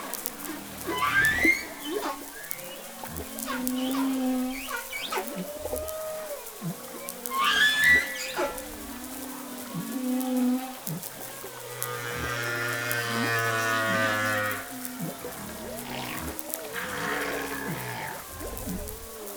Clics Audio